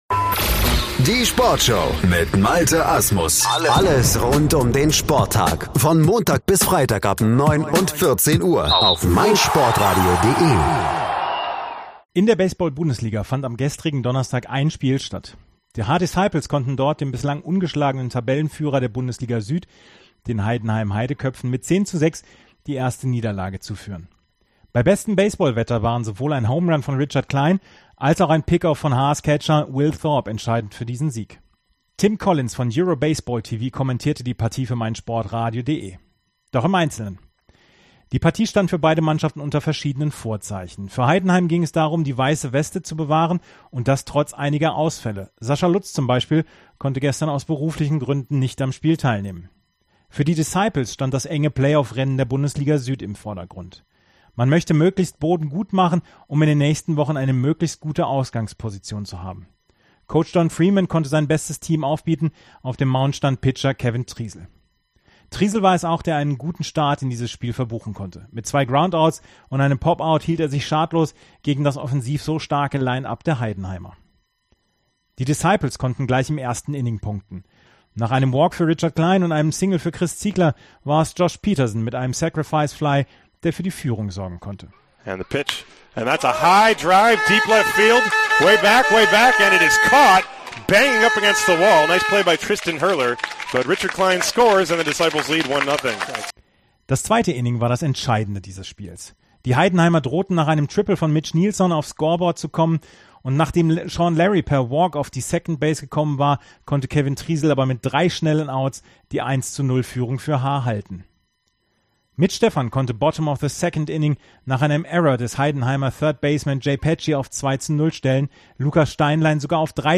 spielbericht-haar-disciples-heidenheim-heidekoepfe.mp3